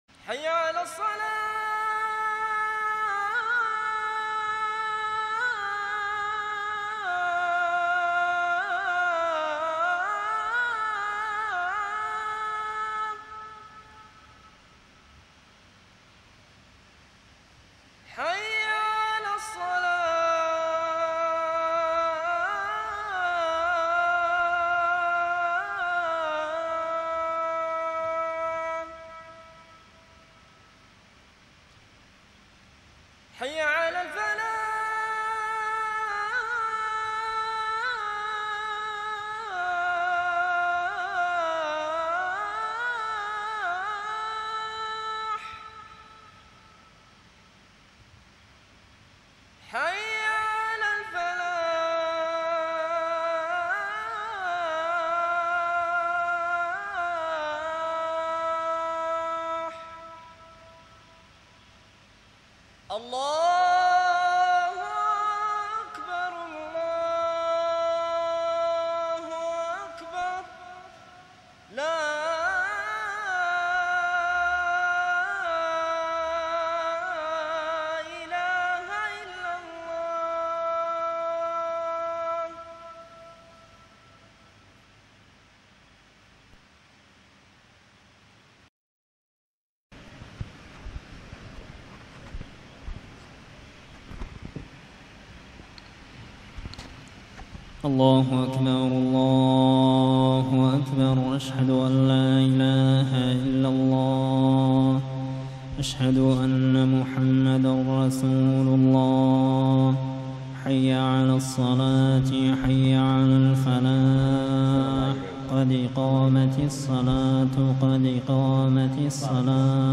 من السودان | صلاة المغرب عام ١٤٣٣هـ لسورتي الفجر - البلد > زيارة الشيخ ماهر المعيقلي لدولة السودان عام ١٤٣٣هـ > المزيد - تلاوات ماهر المعيقلي